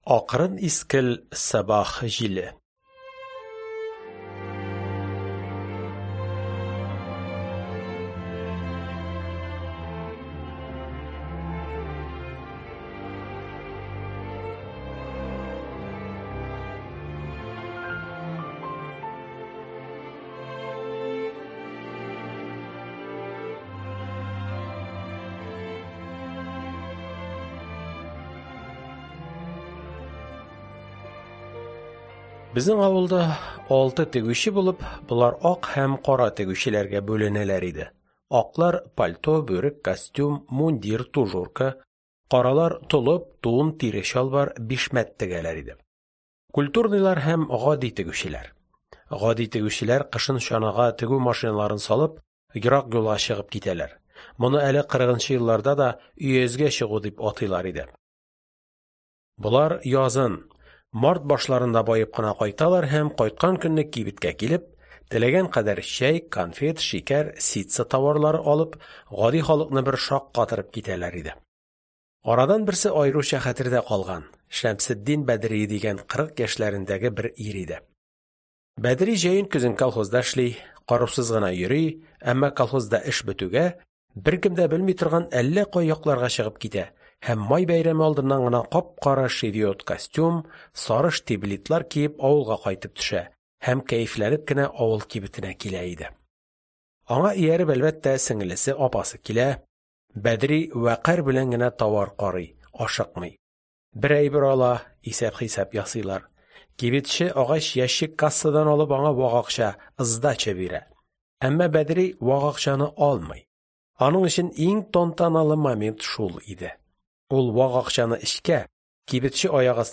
Аудиокнига Торналар төшкән җирдә | Библиотека аудиокниг